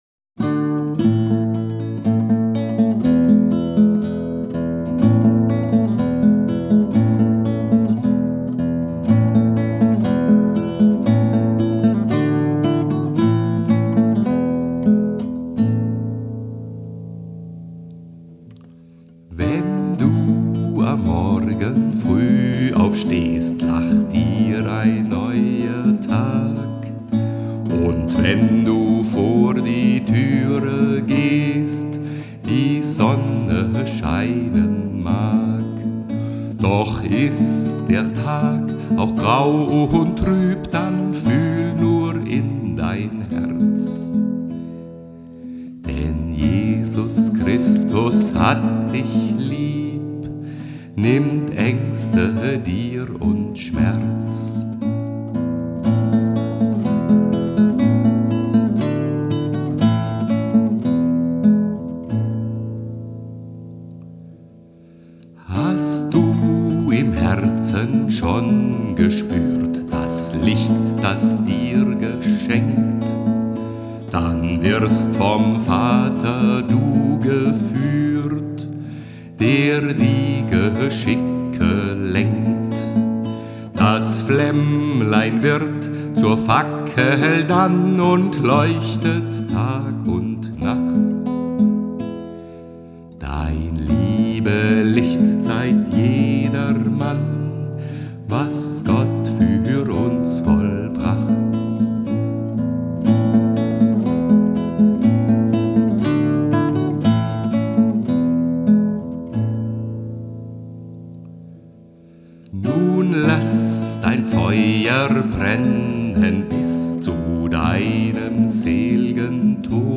Gedicht zum Leben nach dem Tod